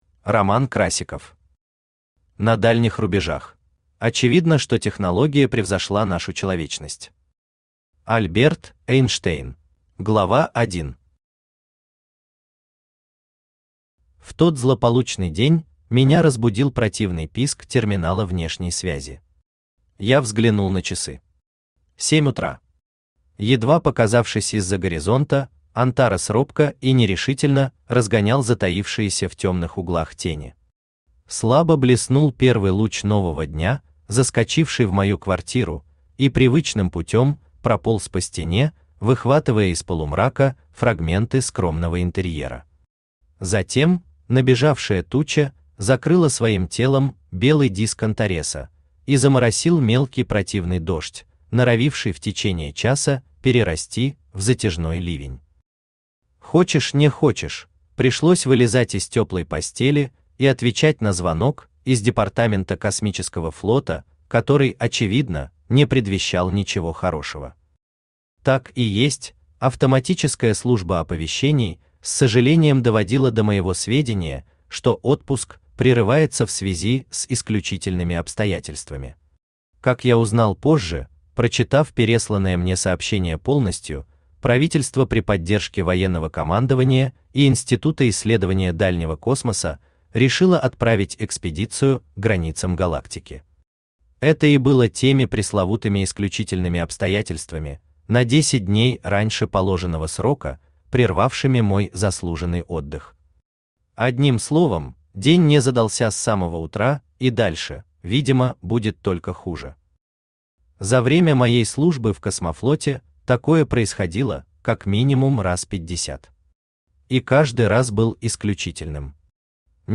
Аудиокнига На дальних рубежах | Библиотека аудиокниг
Aудиокнига На дальних рубежах Автор Роман Борисович Красиков Читает аудиокнигу Авточтец ЛитРес.